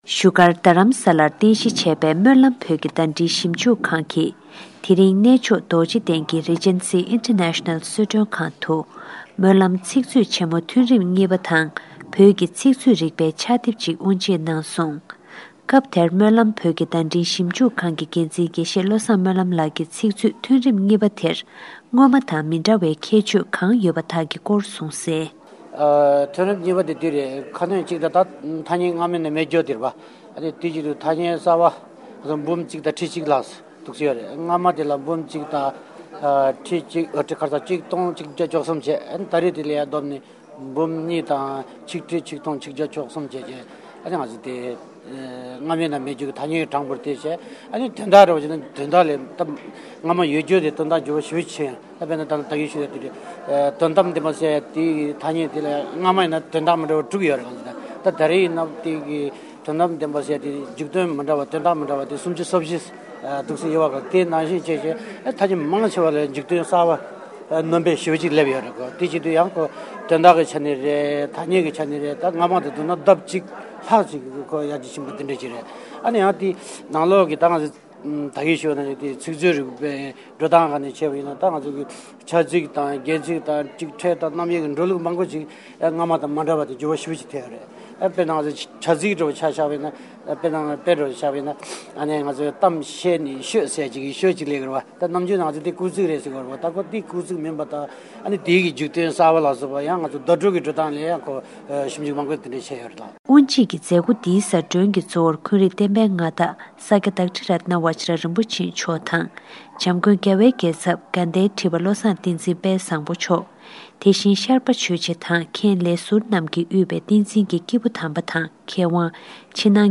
གནས་མཆོག་རྡོ་རྗེ་གནད་ནས་